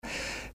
To go a little further, I inserted an original gush from my voice here and there in the silences before the beginning of the refrain.
essaouira-sospirando.mp3